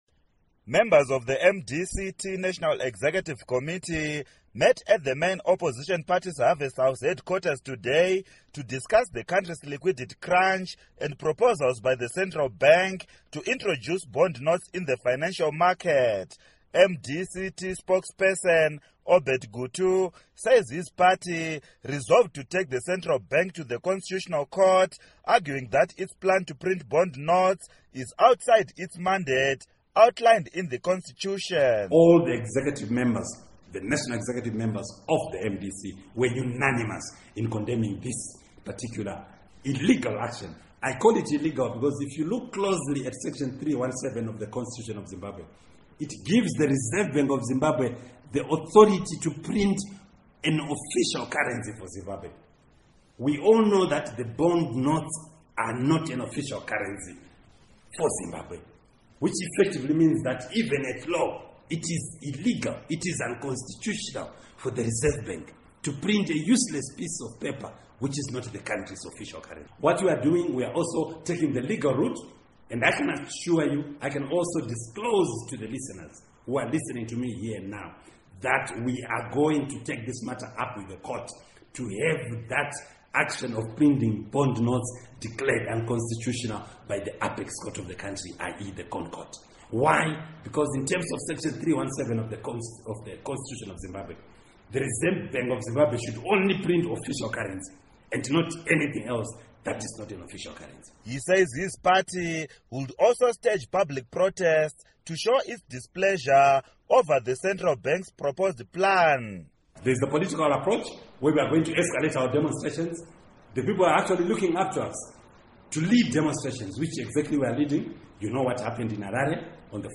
Reports on MDC-T Plans to File A Lawsuit Against Proposed Bond Notes